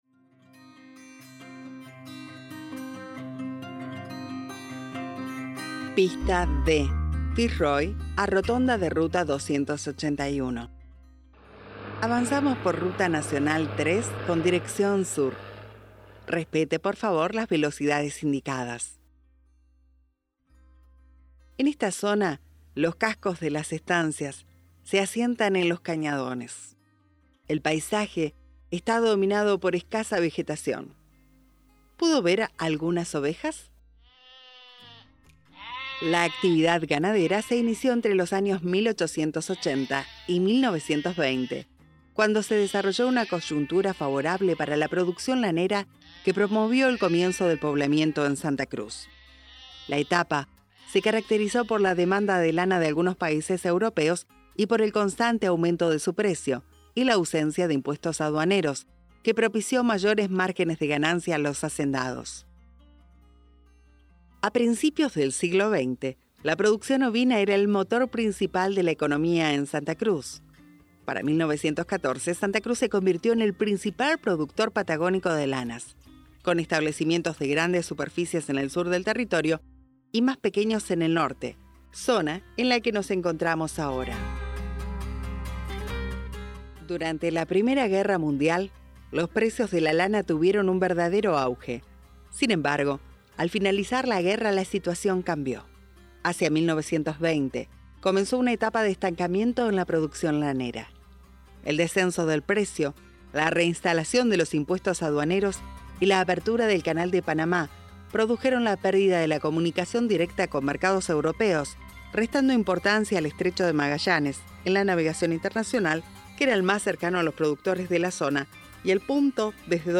Prepárese para descubrir –de la mano de nuestra narradora– sitios que han sido testigos de grandes sucesos, fósiles de arbóreas y de la mega fauna que habitó este lugar millones de años atrás.
Para hacer más amena la propuesta, hemos incluido también producciones musicales de nuestros artistas que ilustran el paisaje y le dan color a este viaje al interior de SANTA CRUZ SONORA: un recurso de comunicación inclusivo que genera la oportunidad de disfrutar del viaje a personas con baja visión.
Bienvenidos a las audioguías vehicular del proyecto "Santa Cruz sonora", que propone la Secretaría de Estado de Turismo de la Provincia, para acompañar a los viajeros en sus travesías por Santa Cruz.